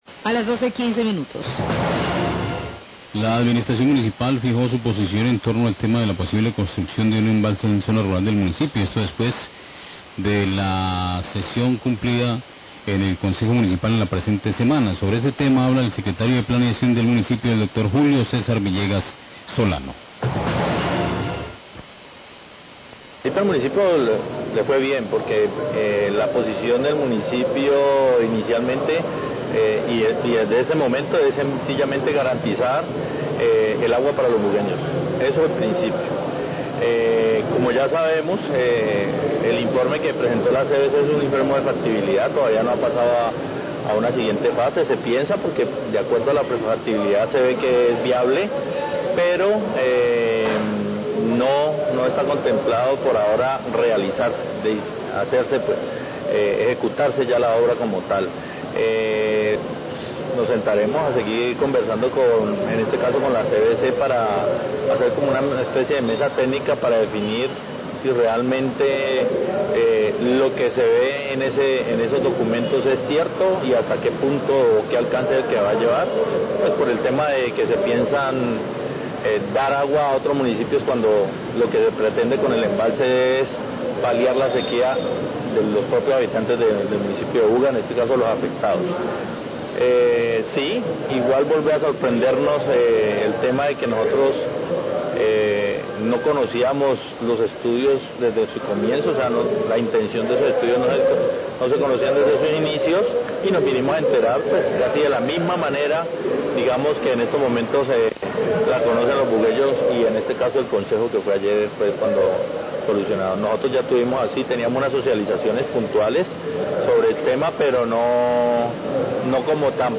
Radio
La Administración Municipal fijó su posición en torno al tema de la posible construcción de un embalse en zona rural del municipio, esto después de la sesión cumplida en el Concejo Local en la presente semana. Sobre este tema habla el secretario de Planeación del municipio, Julio César Villegas.